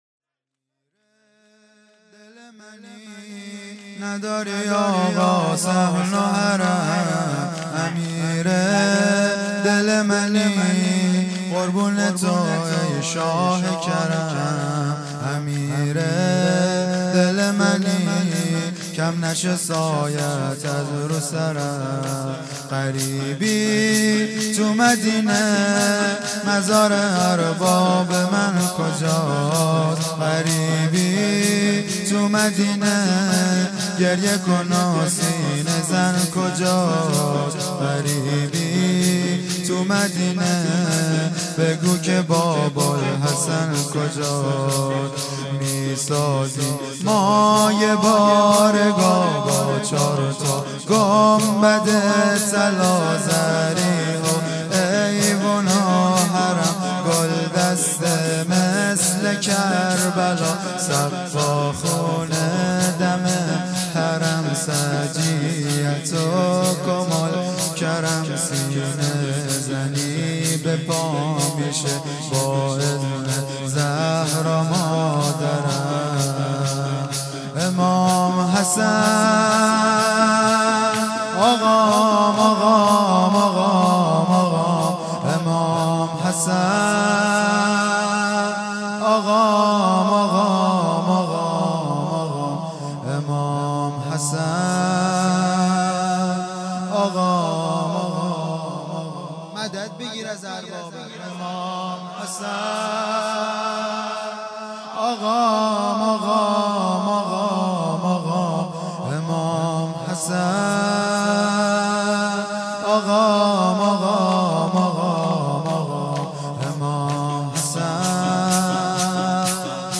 هیئت دانشجویی فاطمیون دانشگاه یزد